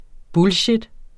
Udtale [ ˈbulɕed ]